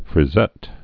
(frĭ-zĕt)